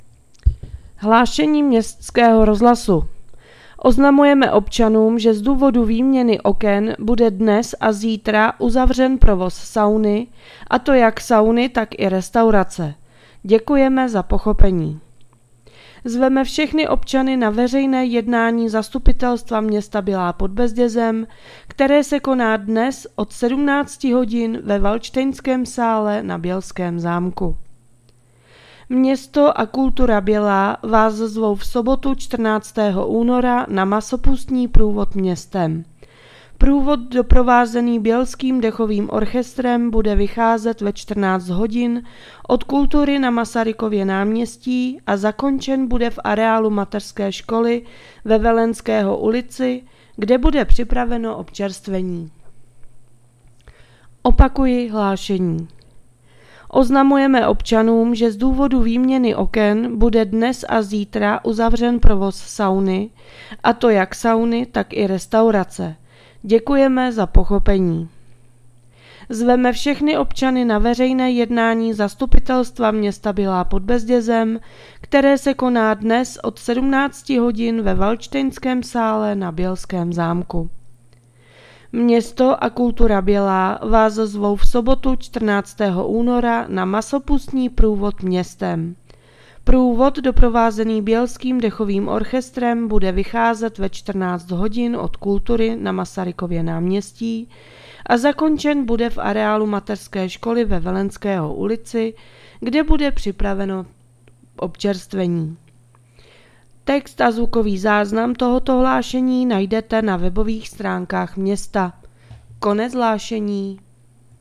Hlášení městského rozhlasu 11.2.2026